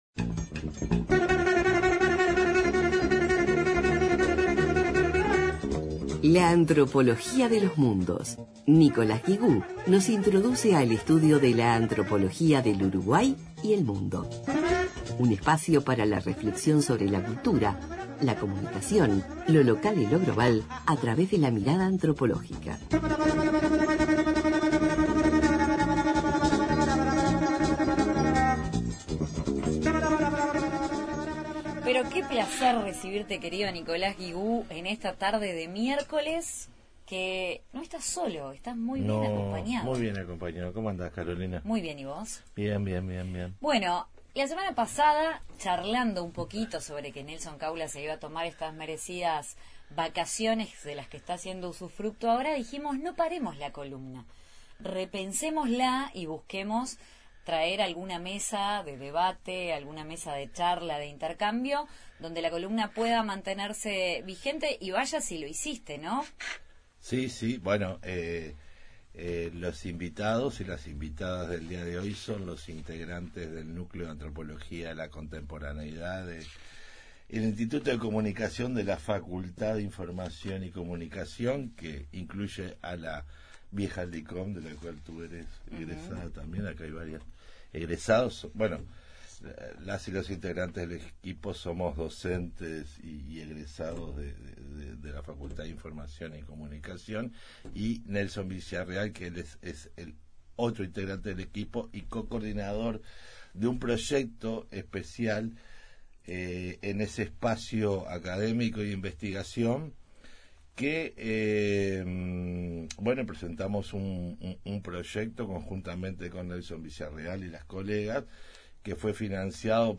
integran la mesa que reflexiona sobre Narrativas, representaciones, memorias y mitologías.